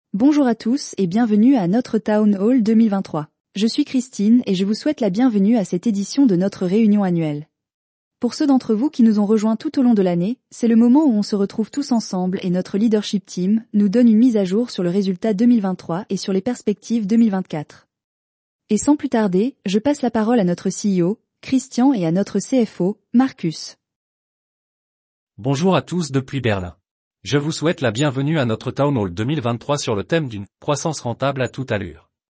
La voix générée par IA est une synthèse vocale produite par des algorithmes d’intelligence artificielle. Elle reproduit des voix humaines réalistes en imitant timbre, intonation et nuances.
Exemple de Voix générée par IA :
Test_voiceoverAI_FR.mp3